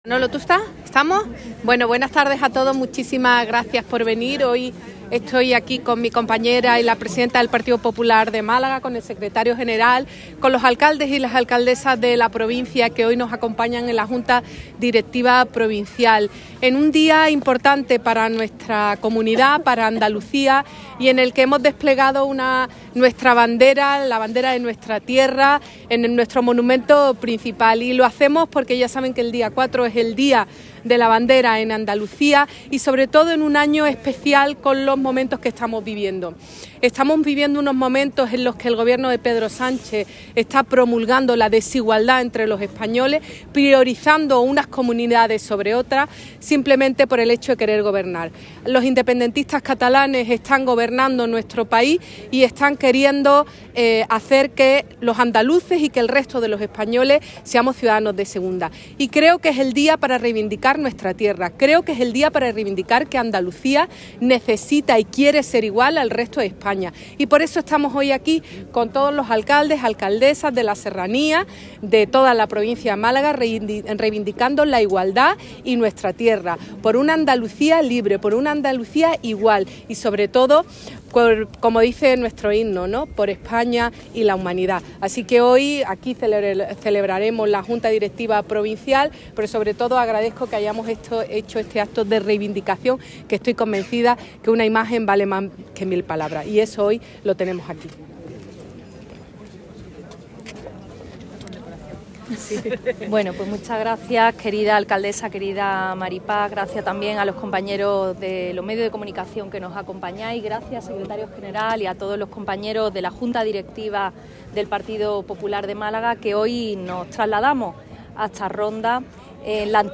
En su intervención ante los medios, la dirigente popular ha aludido a la reciente aprobación en el Parlamento de Andalucía de la petición al Estado de las competencias del transporte ferroviario de Cercanías y un plan de interconexión entre municipios, recordando que esta concesión la ha hecho Sánchez a Cataluña con el Rodalíes, “inyectándole además 6.300 millones de euros antes del traspaso”.